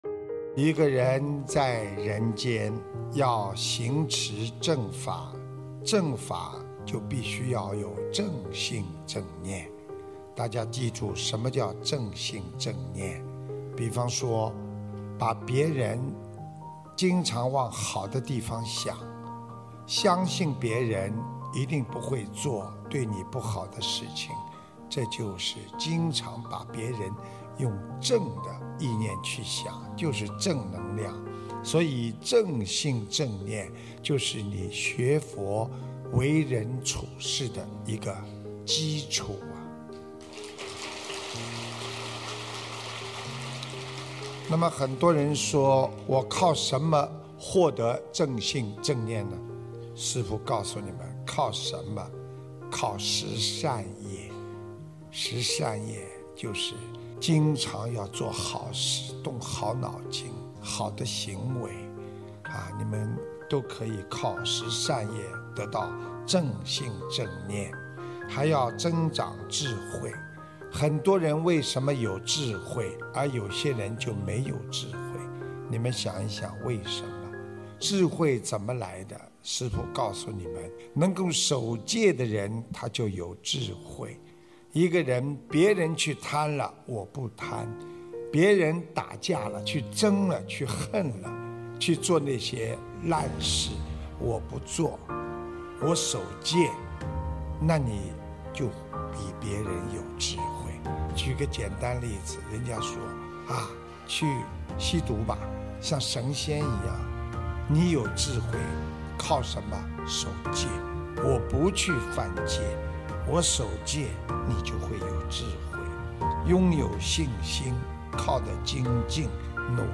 音频：如何拥有正信正念！巴黎世界佛友见面会！